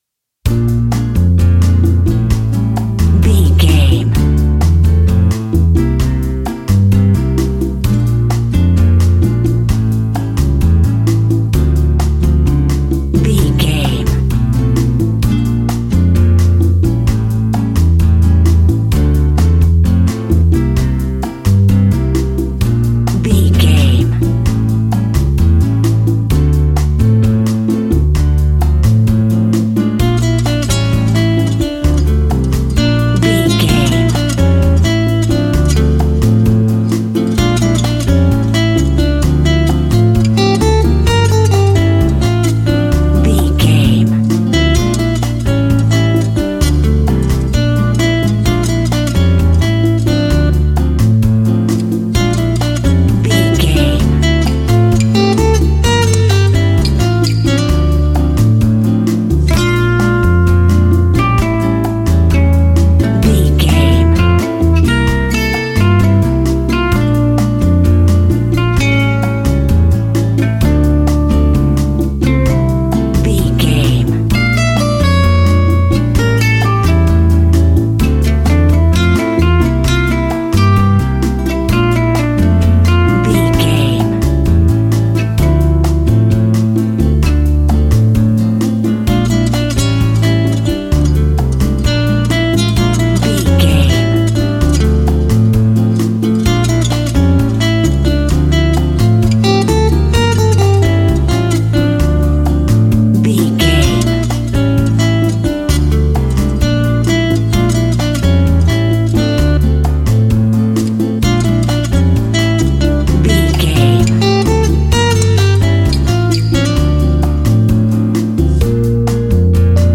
An exotic and colorful piece of Espanic and Latin music.
Aeolian/Minor
maracas
percussion spanish guitar
latin guitar